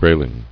[gray·ling]